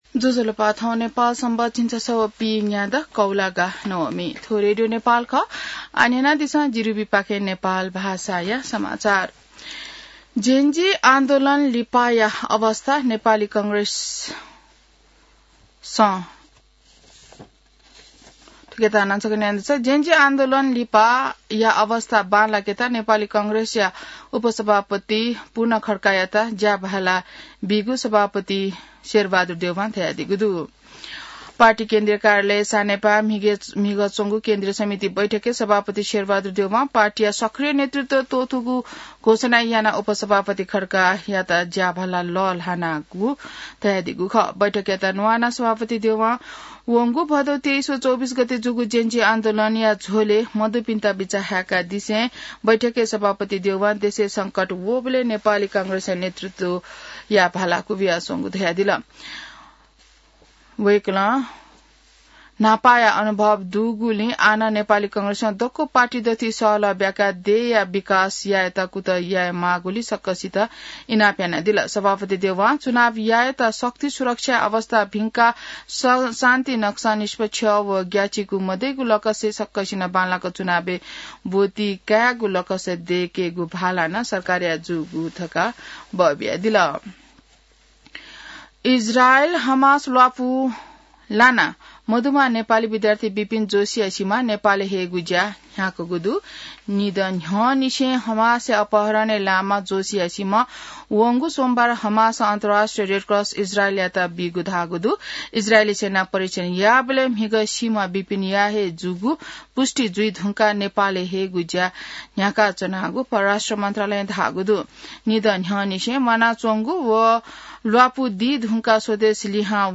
An online outlet of Nepal's national radio broadcaster
नेपाल भाषामा समाचार : २९ असोज , २०८२